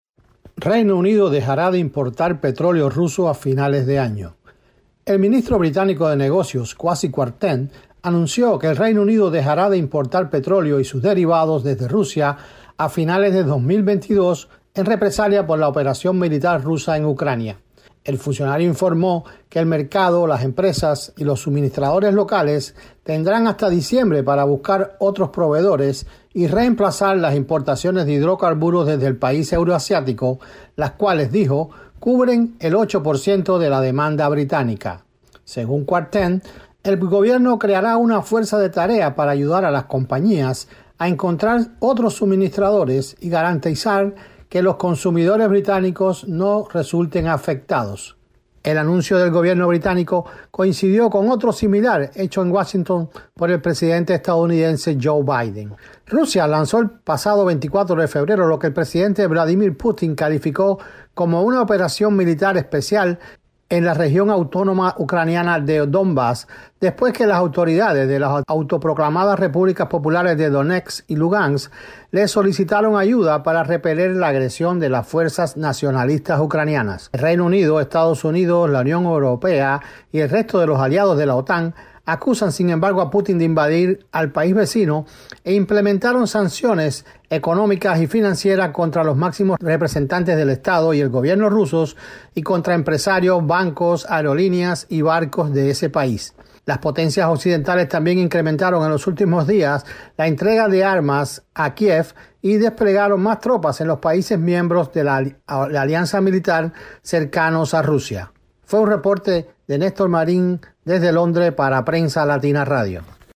desde Londres